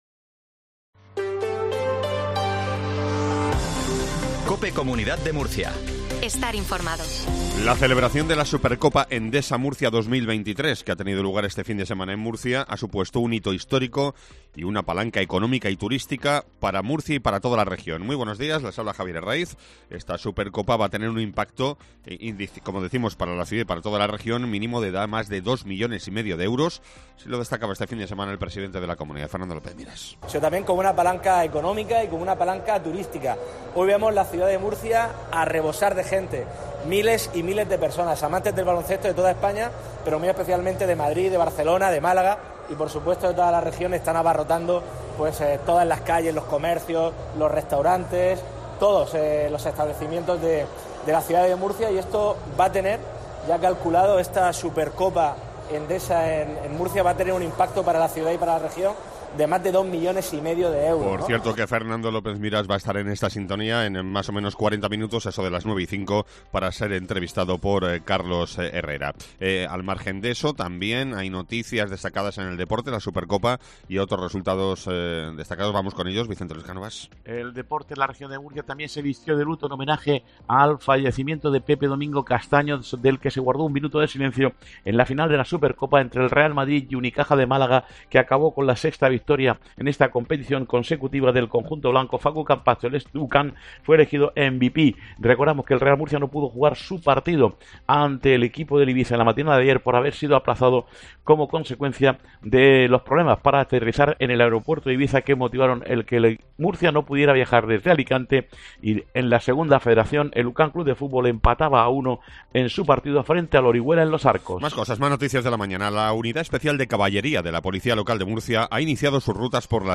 INFORMATIVO MATINAL REGION DE MURCIA 0820